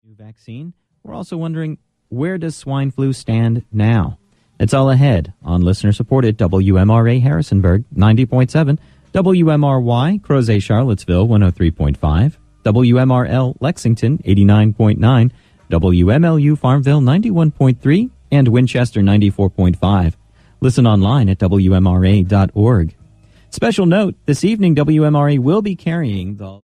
Npr News & Npr Talk
WMRA Top of the Hour Audio: